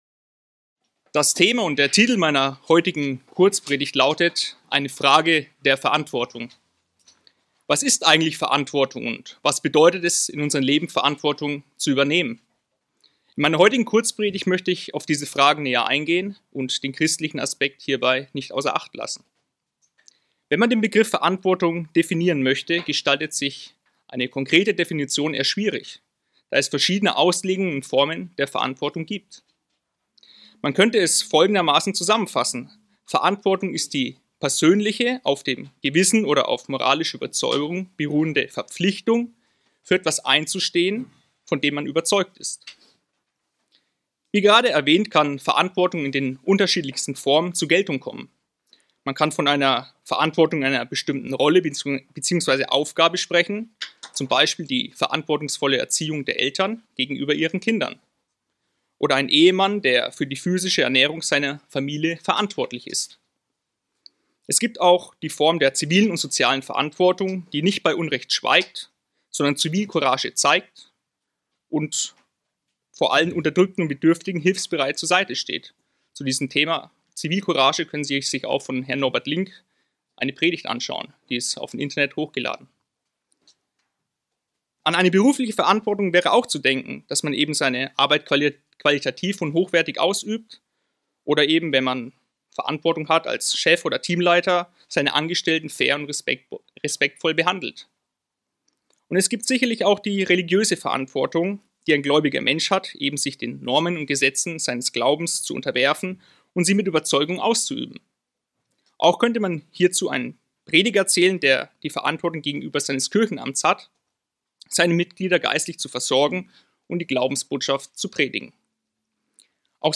Diese Kurzpredigt geht auf die o.g. Fragen näher ein und zeigt auf, wie wir verantwortungsbewusst in einer verdorbenen Welt leben können.